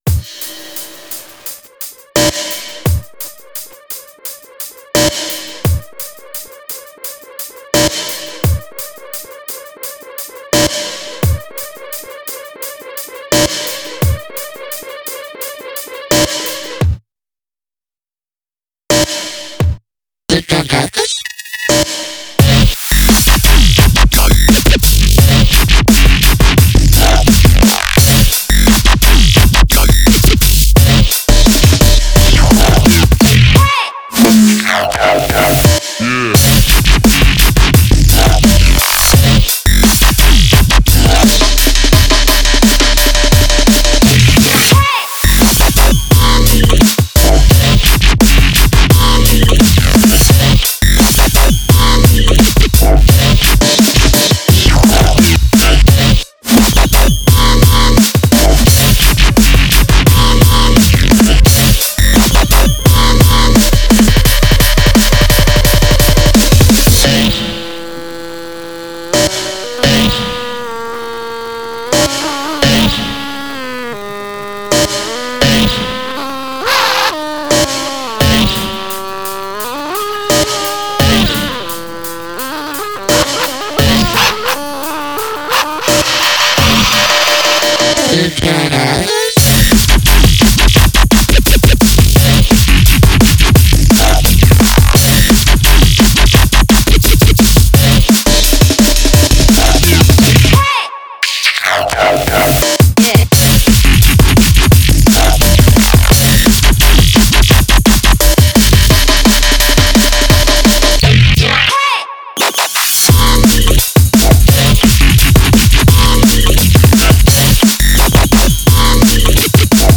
Style: Dubstep, Drum & Bass
Quality: 320 kbps / 44.1KHz / Full Stereo